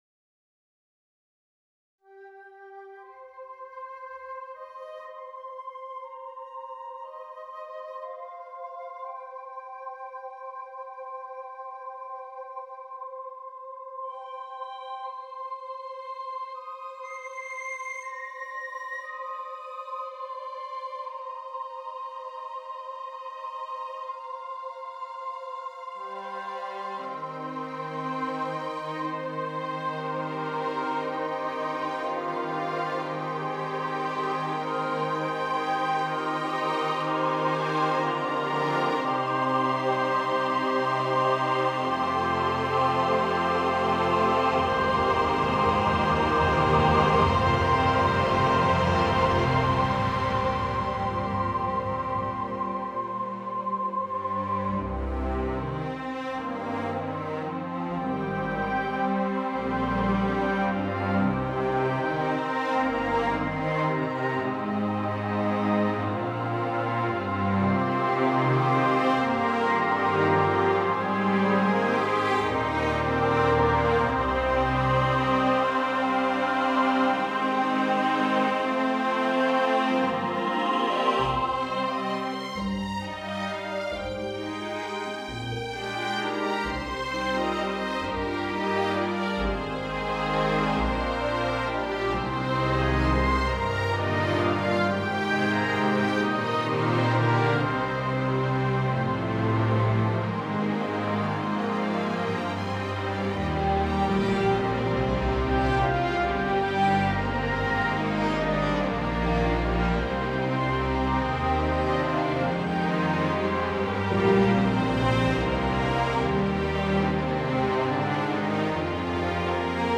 For a large Symphony Orchestra